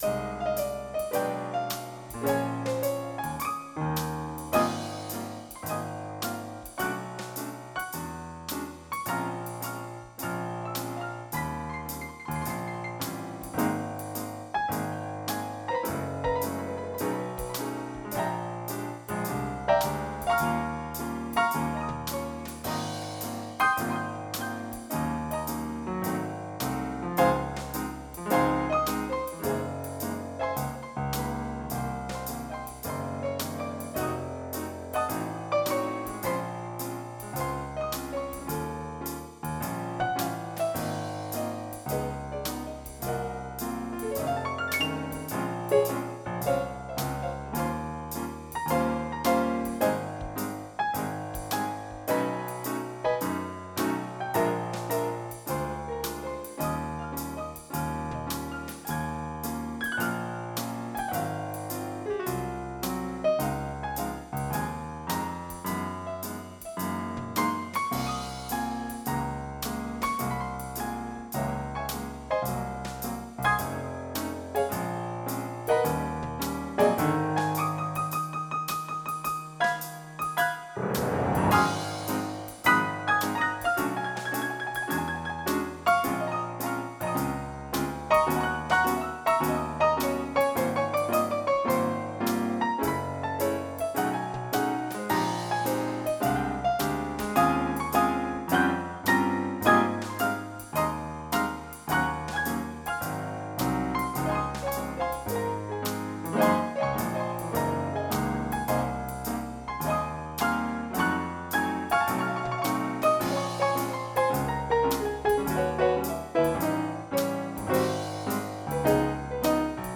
MIDI Music File
JAZZ18.mp3